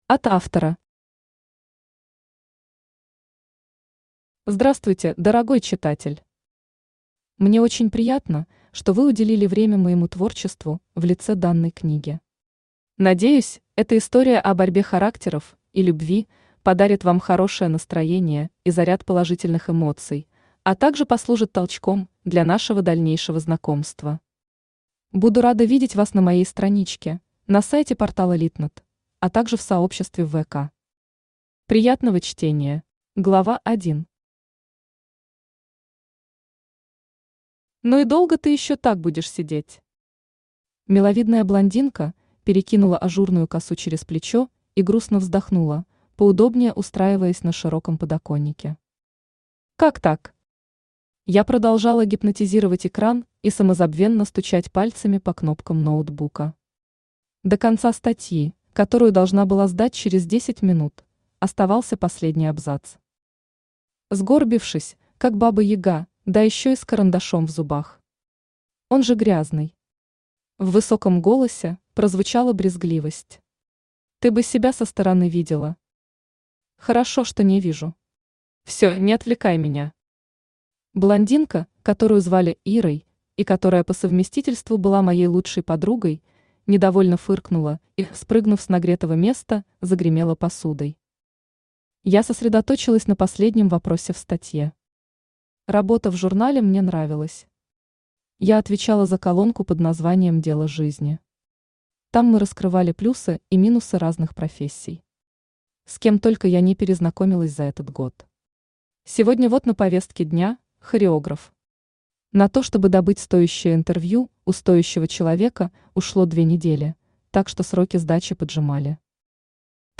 Аудиокнига Небо и Земля | Библиотека аудиокниг
Aудиокнига Небо и Земля Автор Виктория Царевская Читает аудиокнигу Авточтец ЛитРес.